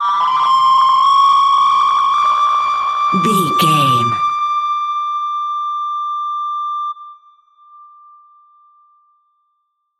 Sound Effects
Atonal
scary
ominous
eerie
synth
ambience
pads